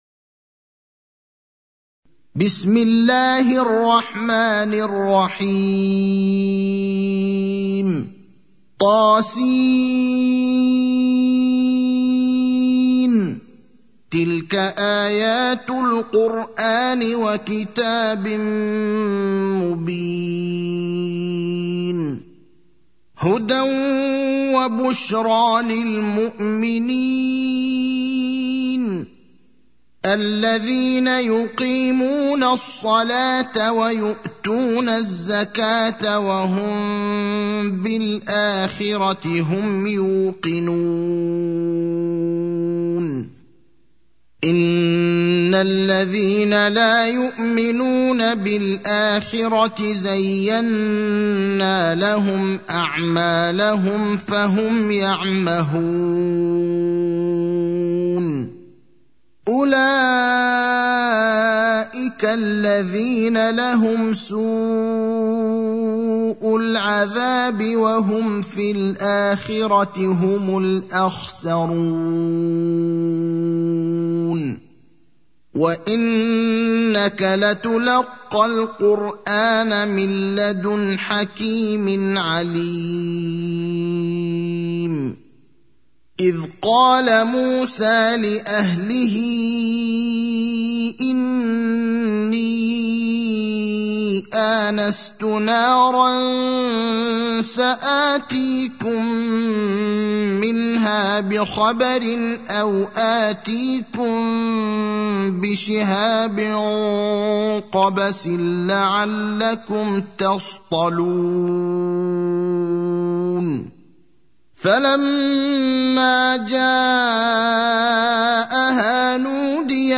المكان: المسجد النبوي الشيخ: فضيلة الشيخ إبراهيم الأخضر فضيلة الشيخ إبراهيم الأخضر النمل (27)